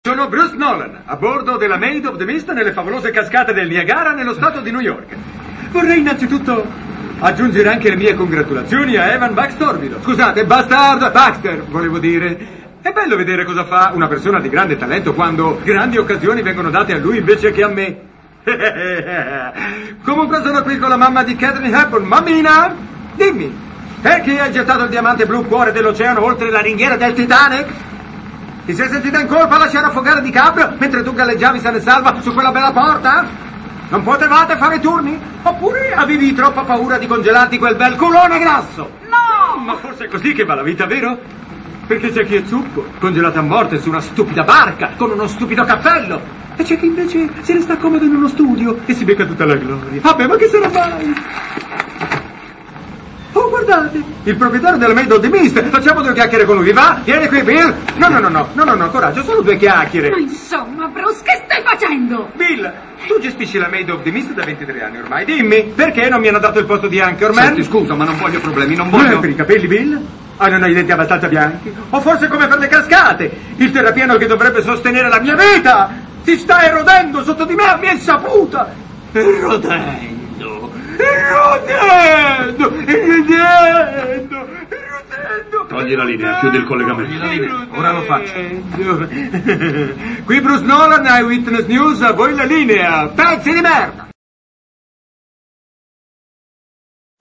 voce di Tonino Accolla nel film "Una settimana da Dio", in cui doppia Jim Carrey.